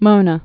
(mōnə)